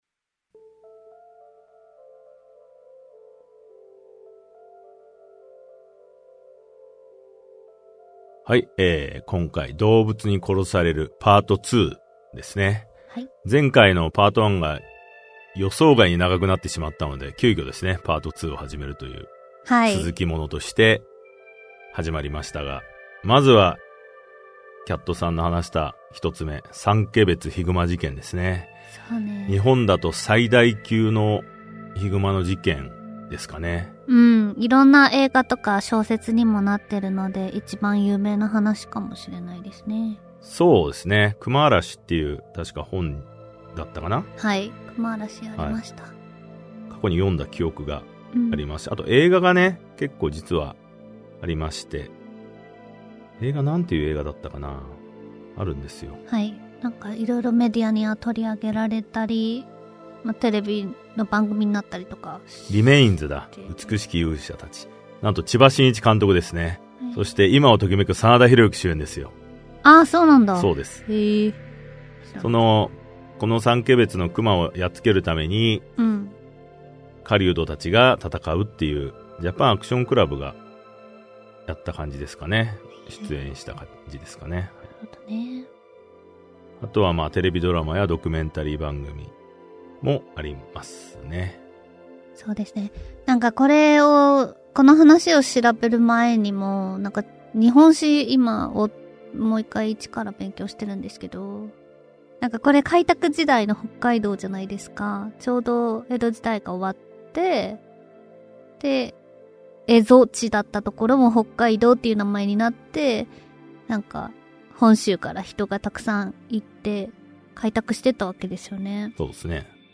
[オーディオブック] トゥルークライム デスラジオセレクション Vol.23 動物に殺される2